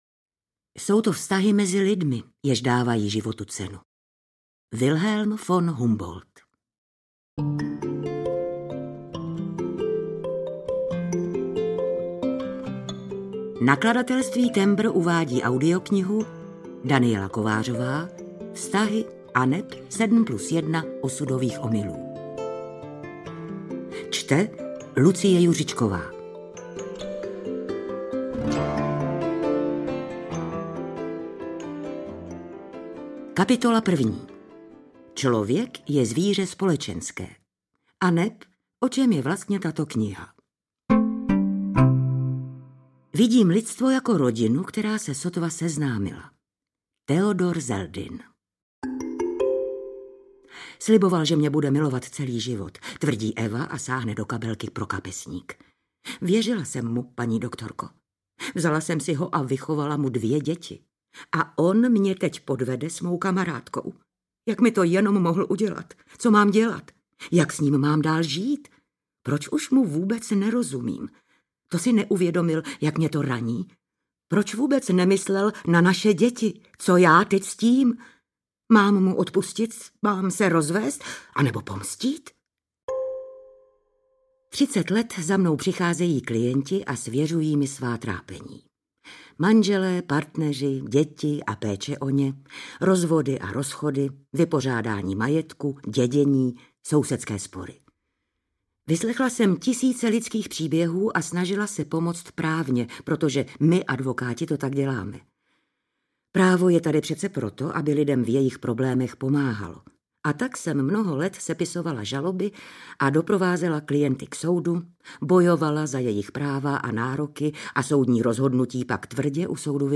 Vztahy, 7+1 osudových omylů audiokniha
Ukázka z knihy
• InterpretLucie Juřičková